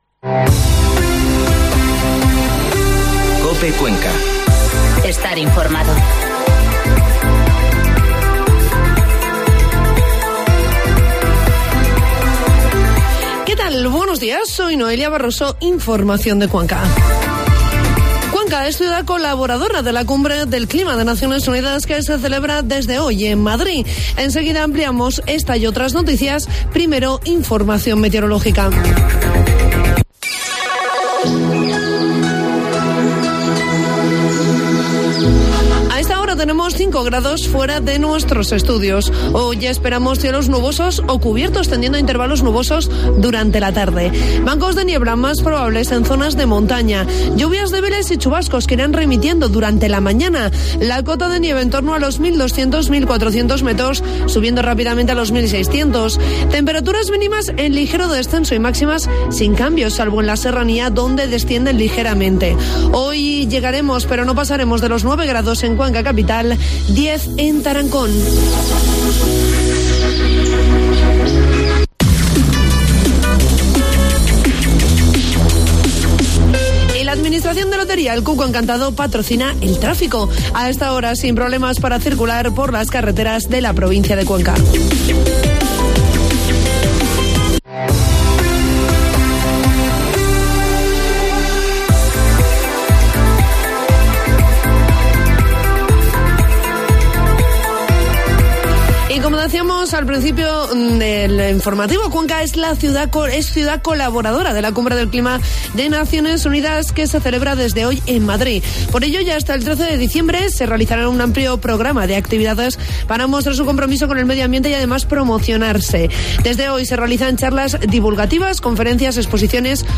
Informativo matinal COPE Cuenca 2 de diciembre